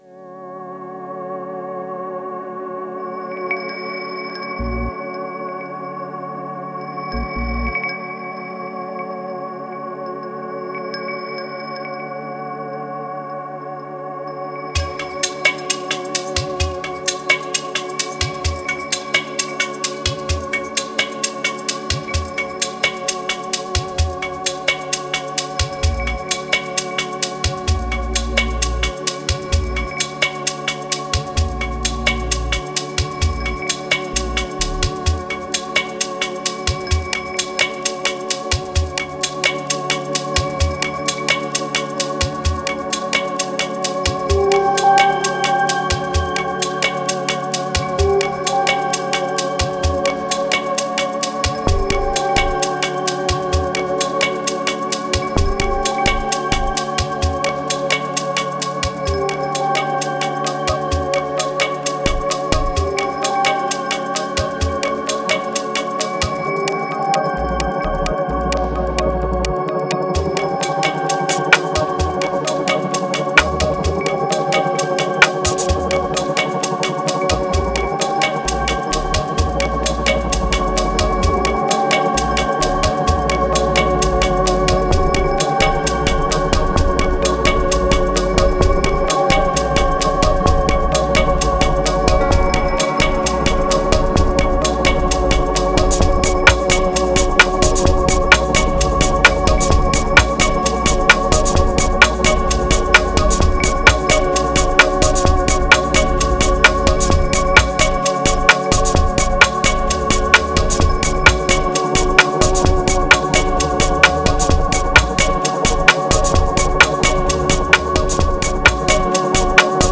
3119📈 - 71%🤔 - 130BPM🔊 - 2016-04-06📅 - 556🌟